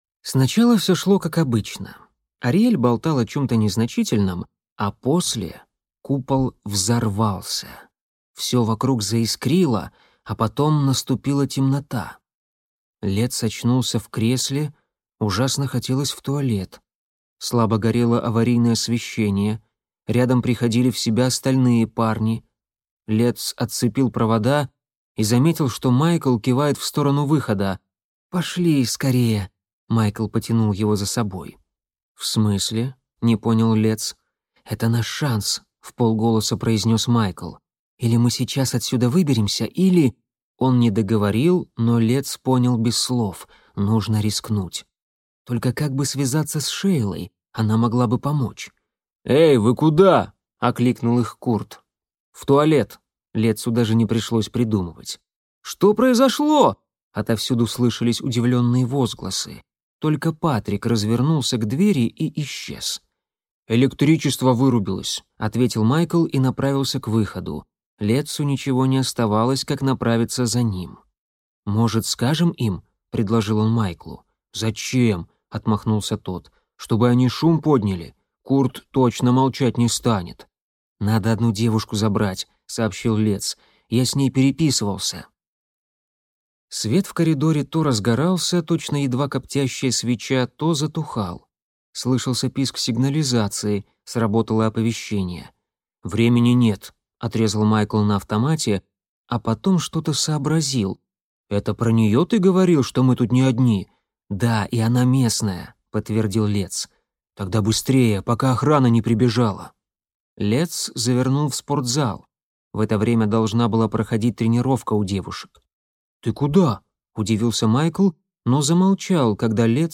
Аудиокнига Темногорье. Серебряный лес | Библиотека аудиокниг